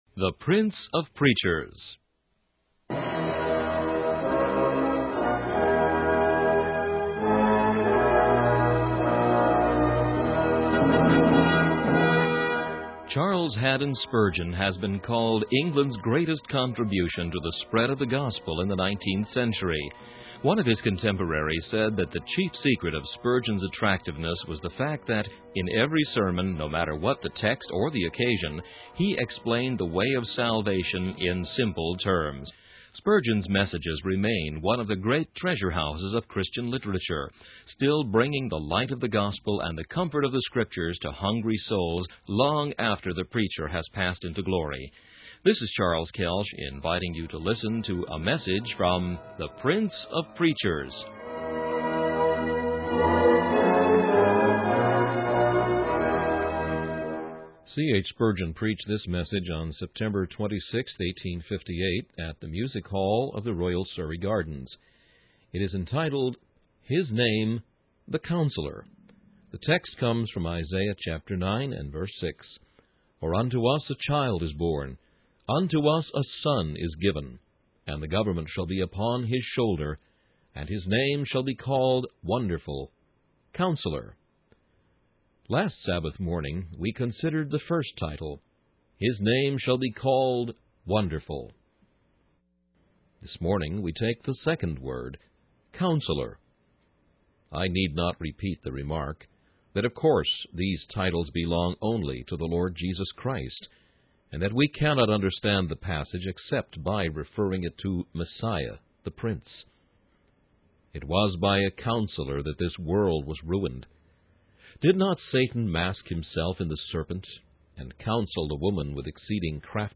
In this sermon, the preacher discusses the title given to Christ as a counselor. He explains that Christ is a counselor in three ways: as God's counselor, as our counselor in times of trouble, and as a counselor for those seeking worldly greatness.